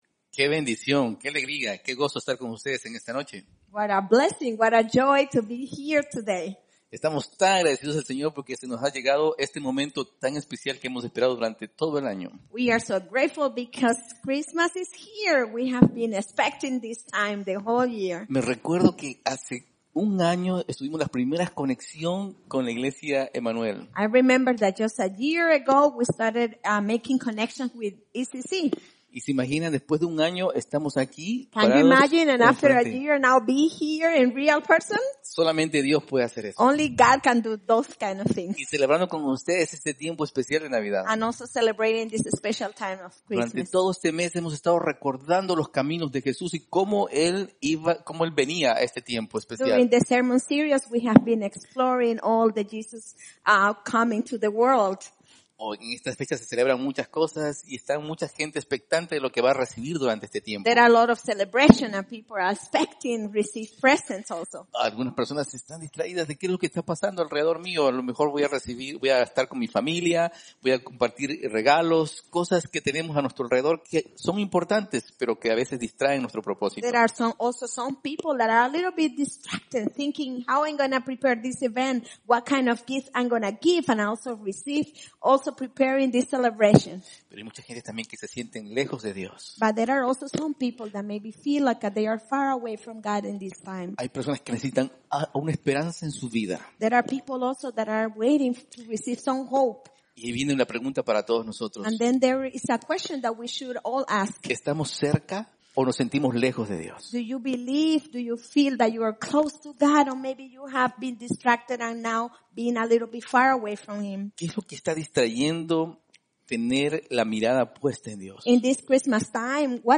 Good News for the Nations Go Tell It On The Mountain Watch Message By